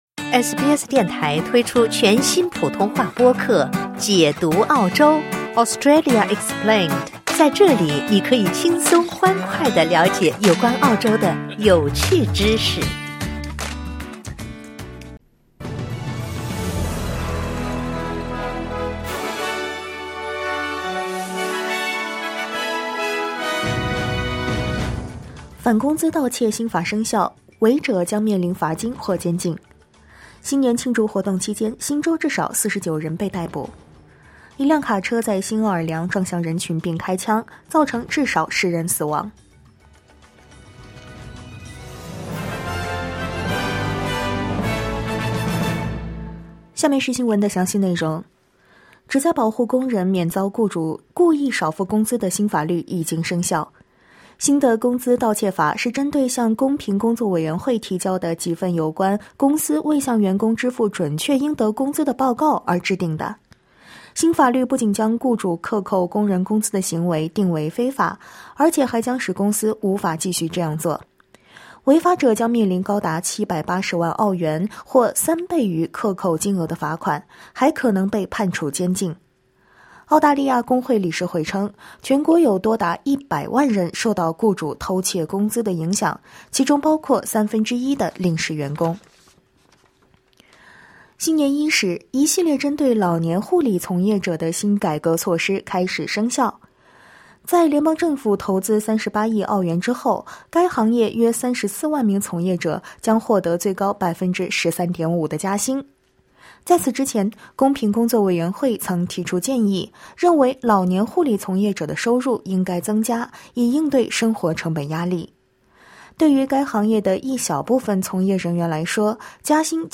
SBS早新闻（2025年1月2日）